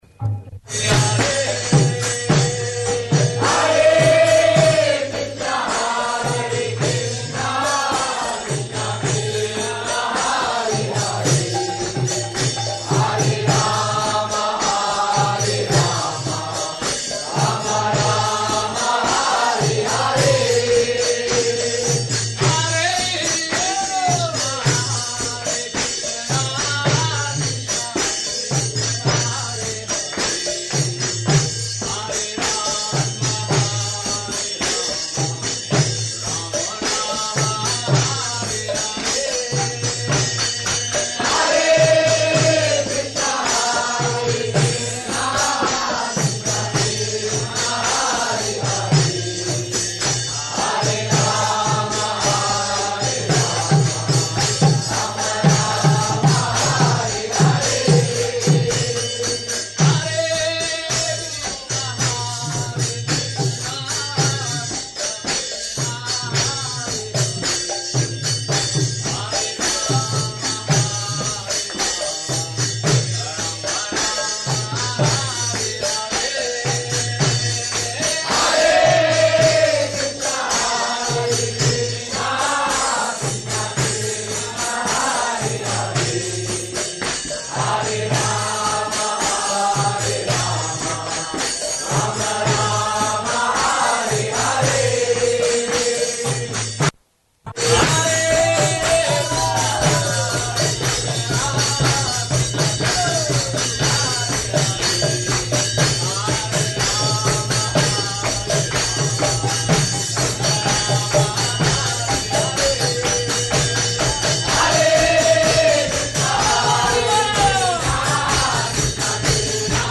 Arrival talk --:-- --:-- Type: Lectures and Addresses Dated: February 12th 1973 Location: Sydney Audio file: 730212AR.SYD.mp3 [ kīrtana ] [10:26] Prabhupāda: [ prema-dvani prayers] ...my coming to Sydney.